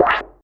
SNARE.118.NEPT.wav